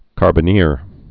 (kärbə-nîr)